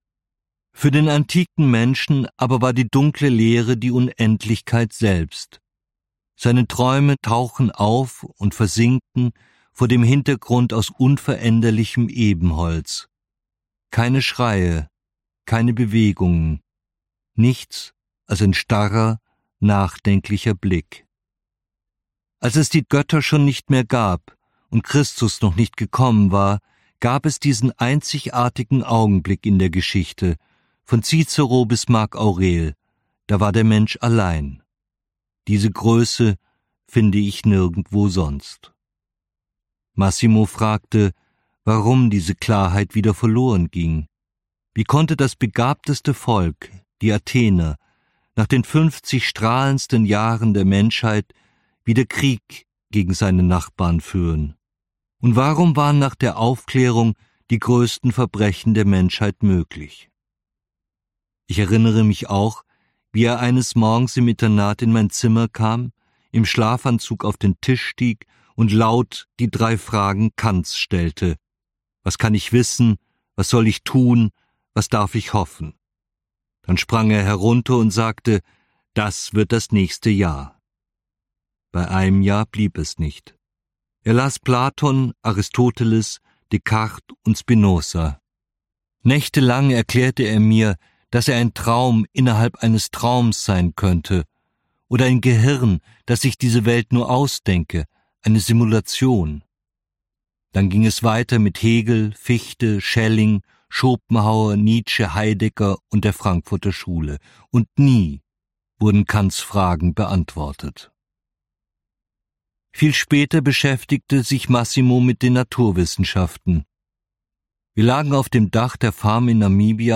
Ferdinand von Schirach (Sprecher)